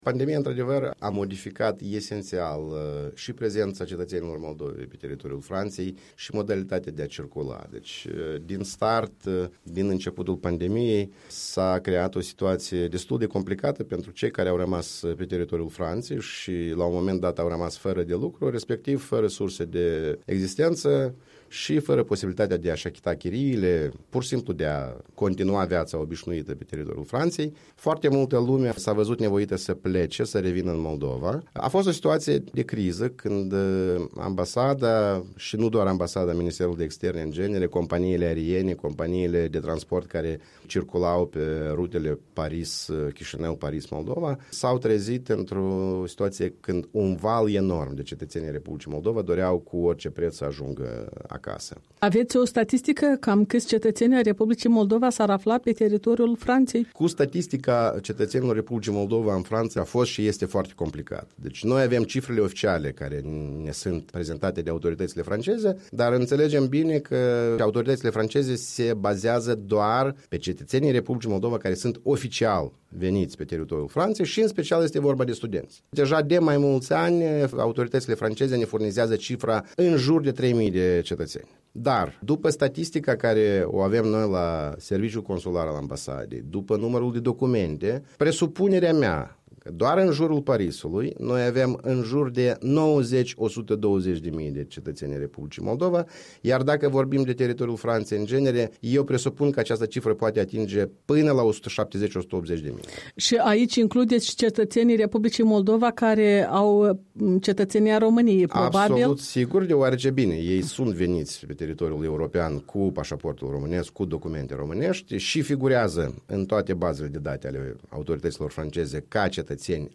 Un interviu cu Eugen Vizir, Consulul General al Republicii Moldova la Paris.